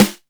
SC LITESNARE.wav